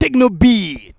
TEKNO BEAT.wav